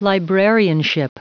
Prononciation du mot librarianship en anglais (fichier audio)
Prononciation du mot : librarianship